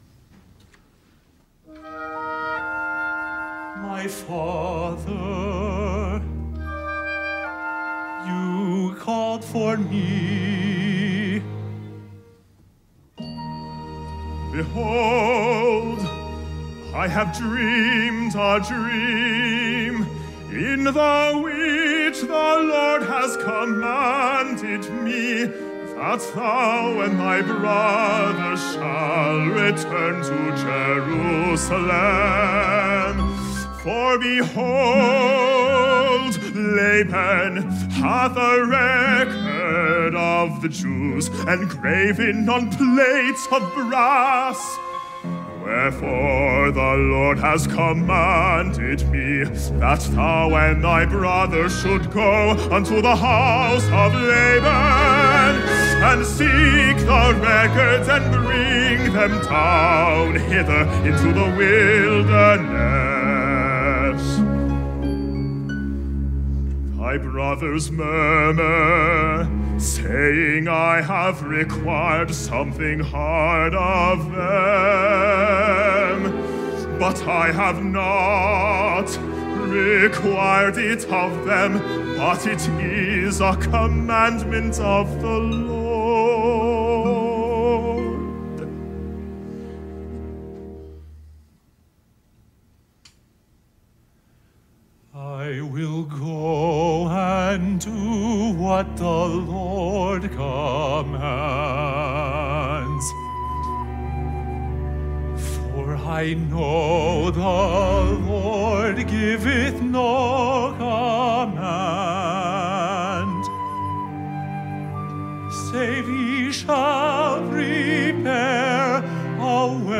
Piano OR Fl, Ob, Cl, Bn, Hn, Tr, Perc, Hp, Pno, and Str
Duet (Nephi and Lehi)